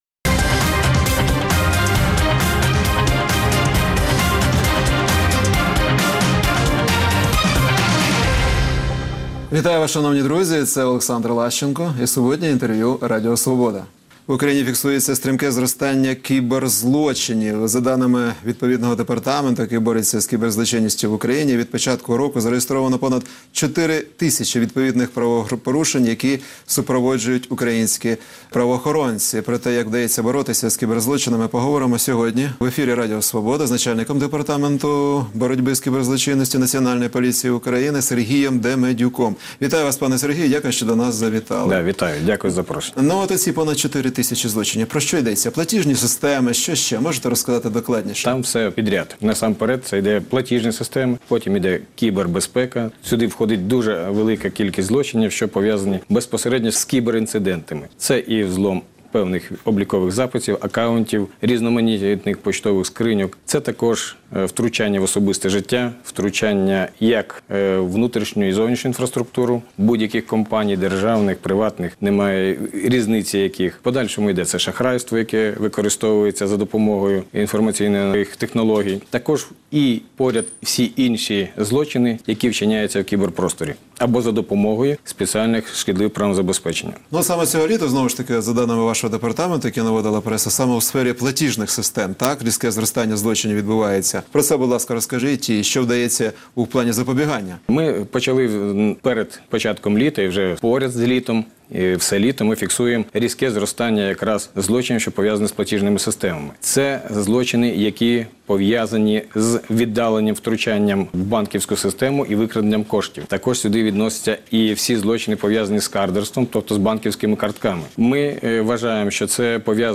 Суботнє інтерв’ю | Сергій Демедюк, начальник Департаменту кіберполіції Національної поліції України
Суботнє інтвер’ю - розмова про актуальні проблеми тижня. Гість відповідає, в першу чергу, на запитання друзів Радіо Свобода у Фейсбуці